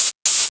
Session 08 - Hi-Hat 01.wav